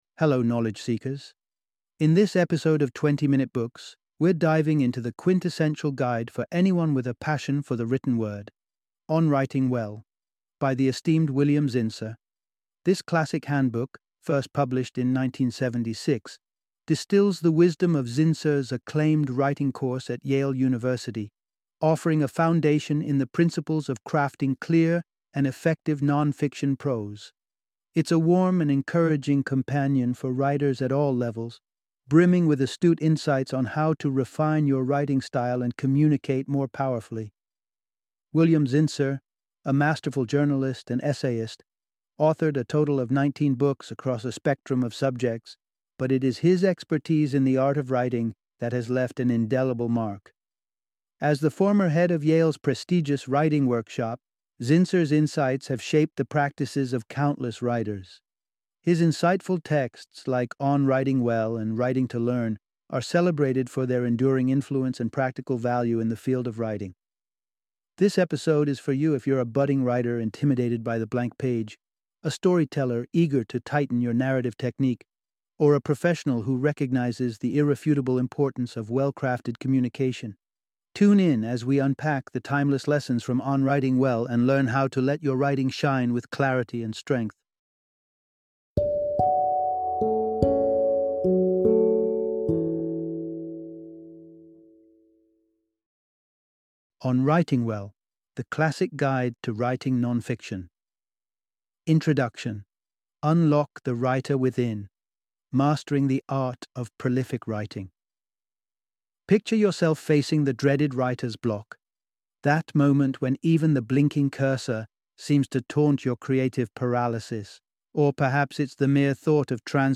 On Writing Well - Audiobook Summary